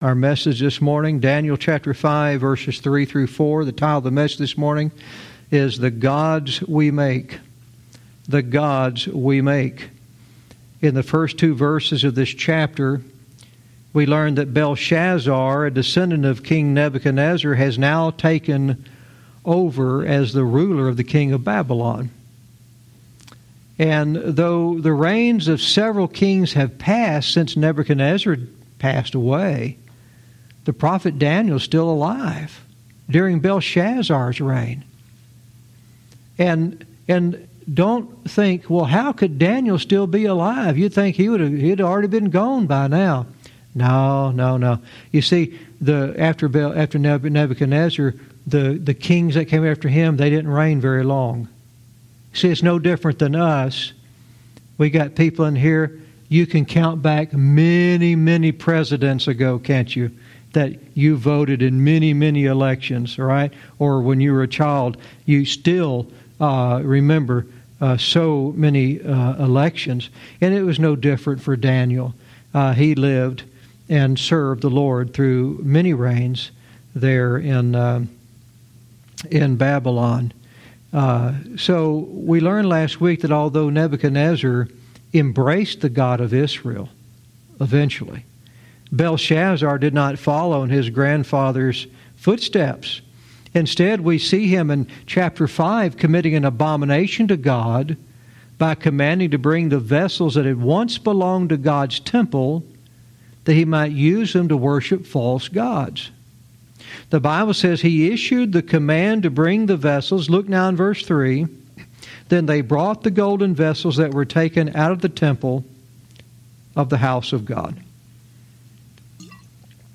Verse by verse teaching - Daniel 5:3-4 "The Gods We Make"